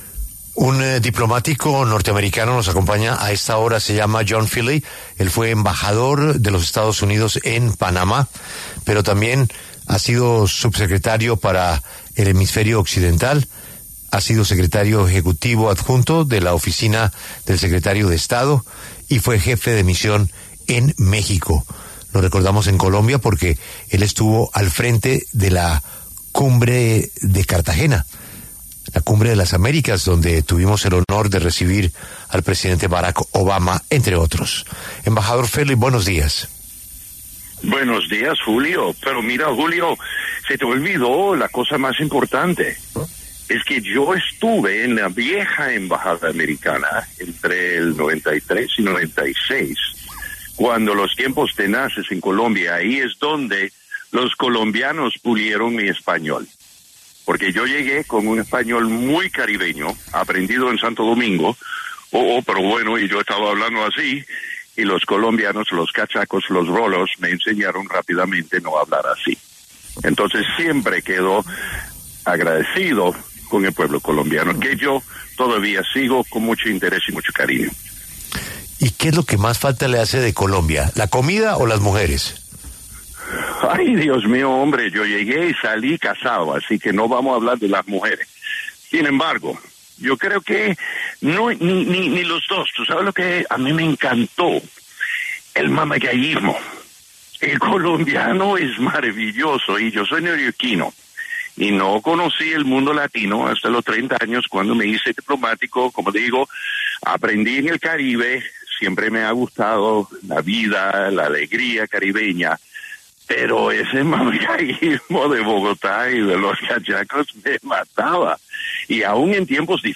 John Feeley, subsecretario adjunto principal para Asuntos del Hemisferio Occidental, habló en La W sobre la petición del concejal José Jaime Uscátegui a Estados Unidos de investigar al Gobierno Petro por su pregunto apoyo al régimen de Maduro.